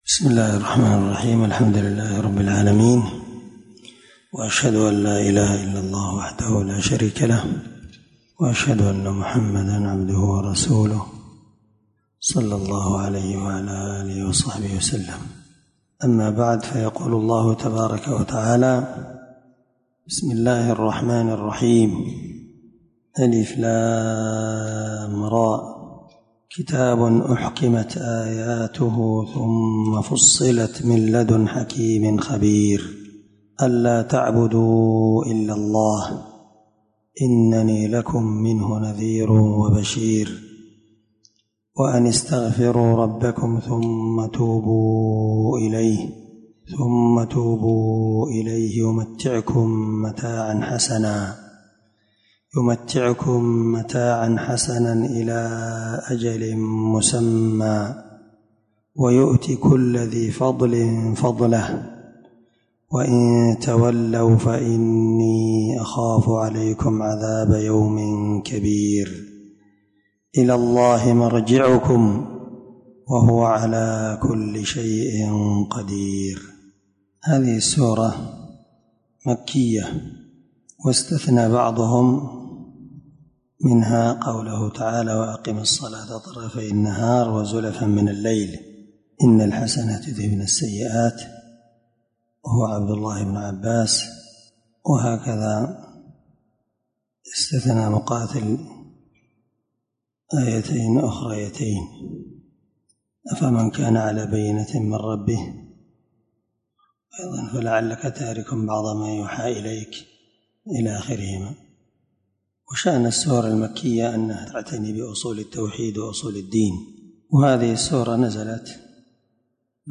620الدرس 1تفسير آية ( 1- 4) من سورة هود من تفسير القران الكريم مع قراءة لتفسير السعدي
دار الحديث- المَحاوِلة- الصبيحة.